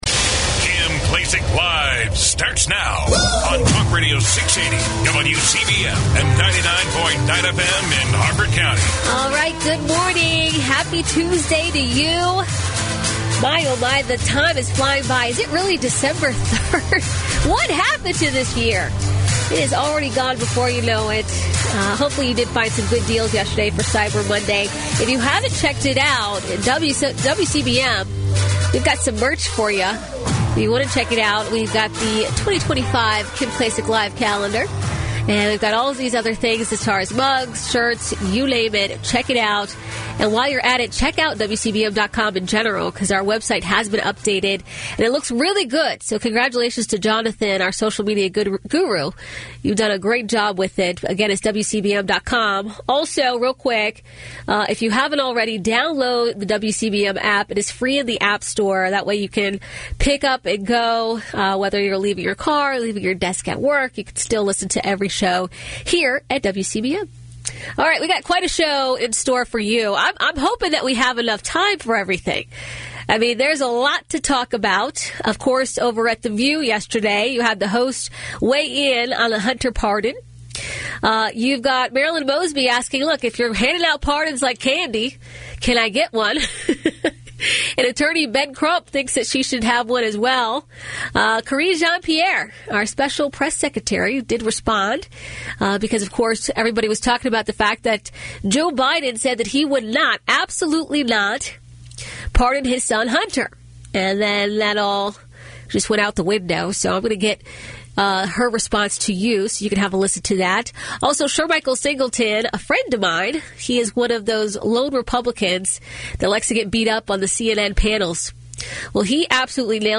Kim Klacik is a dynamic voice who isn’t afraid to speak her mind.
Don’t miss your chance to hear from the one and only Kim Klacik live on WCBM weekdays from 9am to noon.